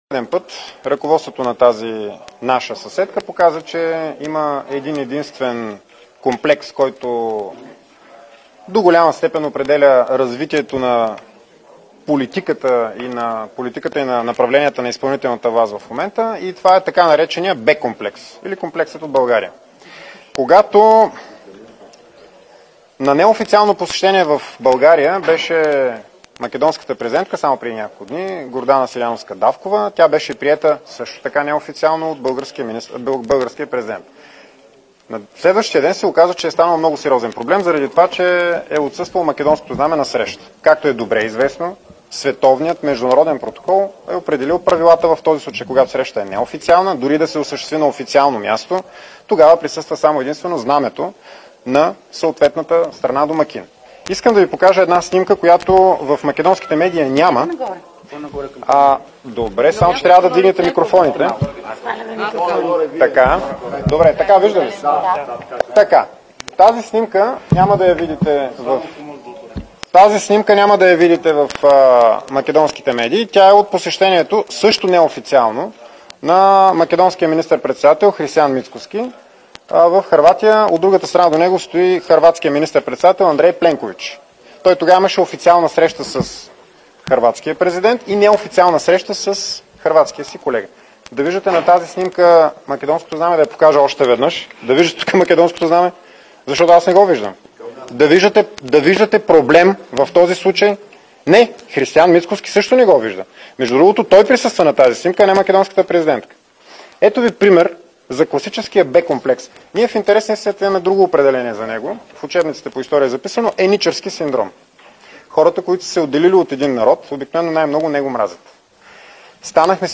9.50 - Брифинг на председателя на ГЕРБ Бойко Борисов.  - директно от мястото на събитието (Народното събрание)